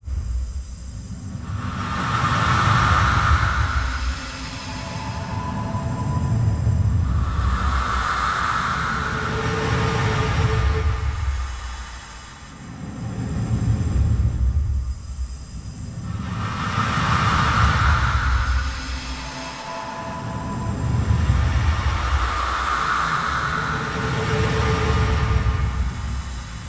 Here are the results of some tests I did on the default 8.04x stretch.
Material with drums and percussion came out a bit hissy, but you might find use for that I guess (my result sounded a bit spooky).
paulstretch_beat_stretched_804.mp3